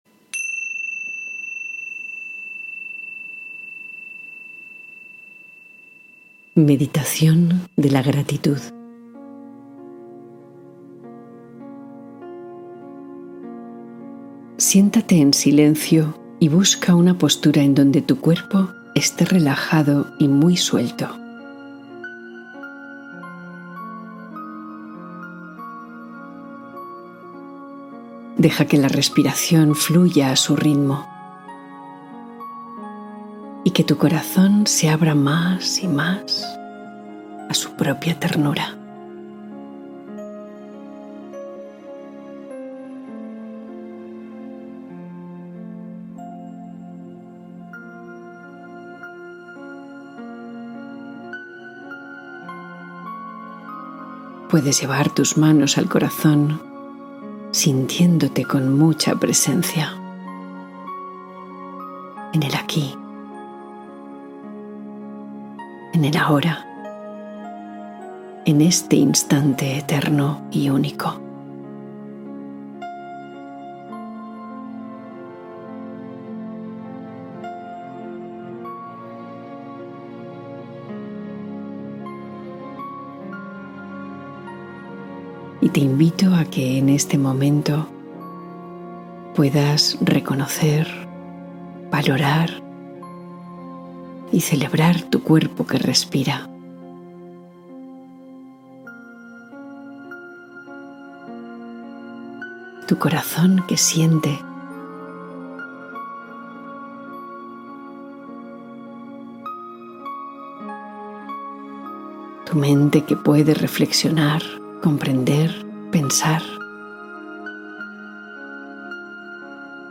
Meditación de gratitud: despierta el poder de la gratitud cada día